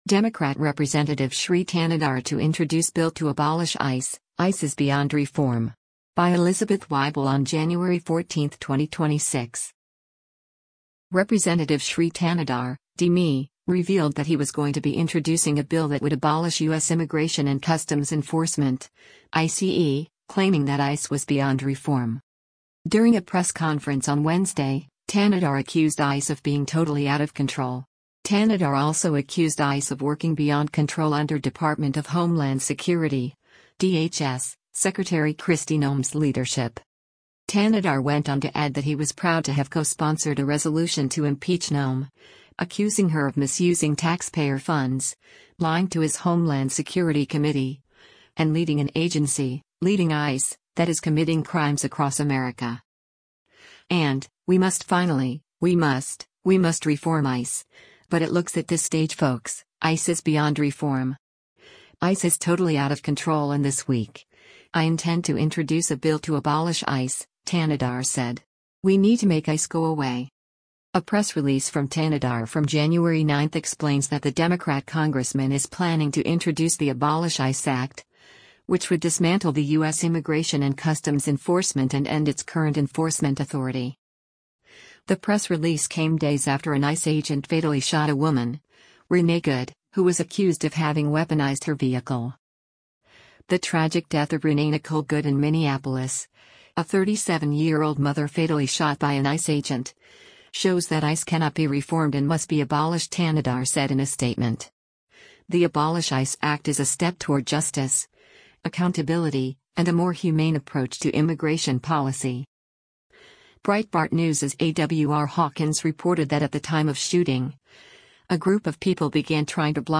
Congressman Shri Thanedar (D-MI) speaks at a press conference with other Democratic member
During a press conference on Wednesday, Thanedar accused ICE of being “totally out of control.”